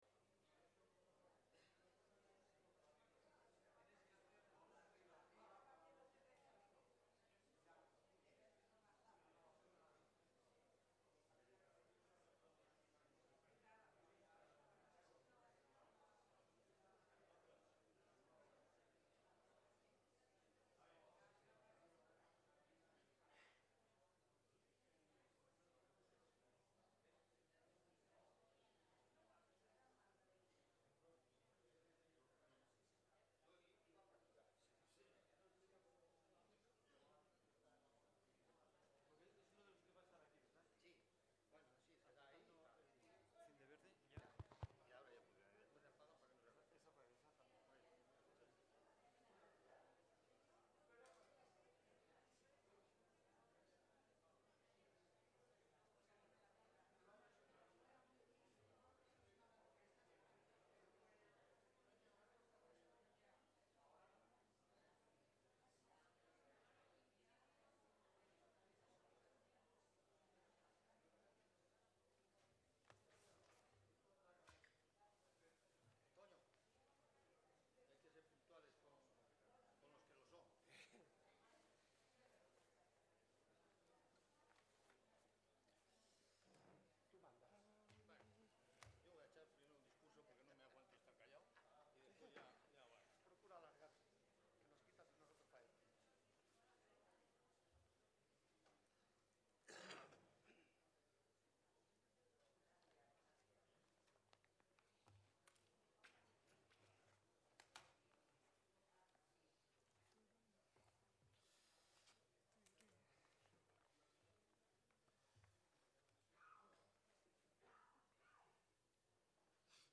El 13 de diciembre de 2011 tiene lugar esta conferencia